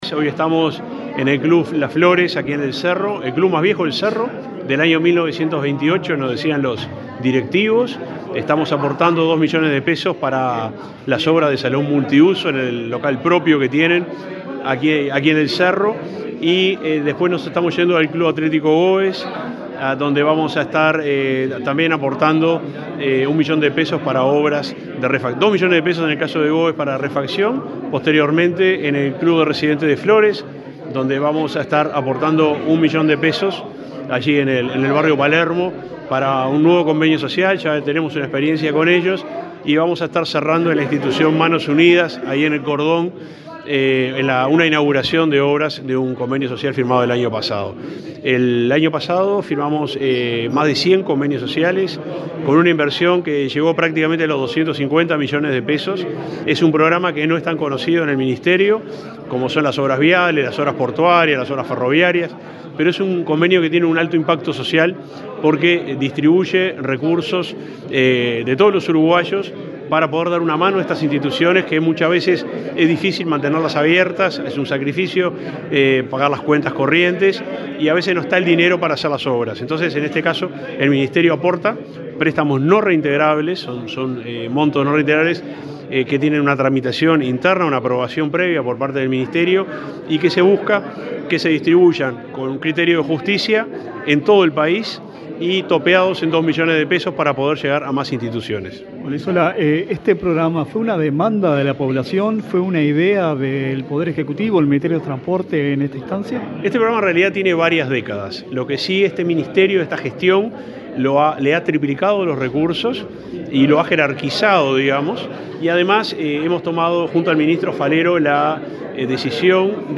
Declaraciones del subsecretario de Transporte, Juan José Olaizola
El subsecretario de Transporte, Juan José Olaizola, dialogó con la prensa en Montevideo, durante la inauguración de obras y la firma de convenios